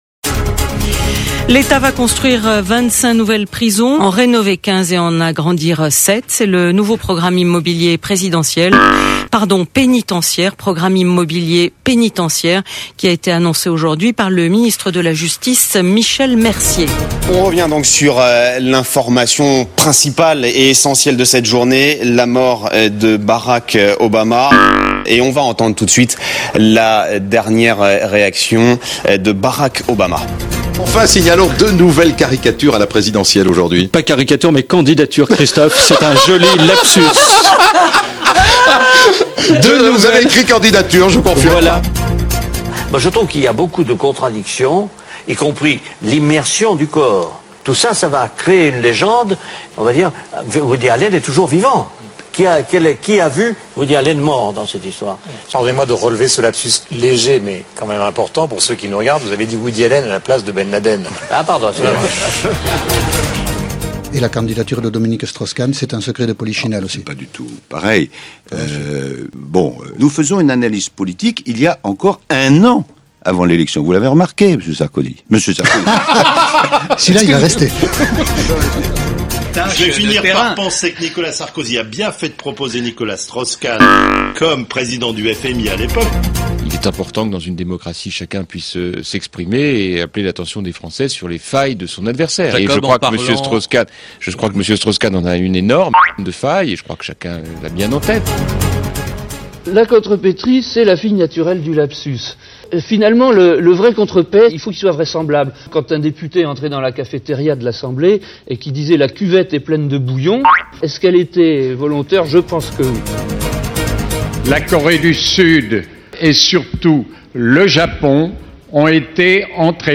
Lapsus, erreurs de noms (ou de prénoms), gaffes...